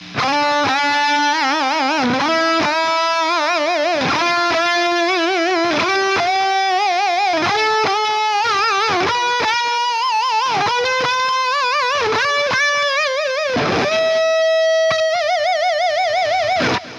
A good way to improve your intonation is to play the note you wish to bend to by itself, go back to the previous note in the scale, and then bend up to that note.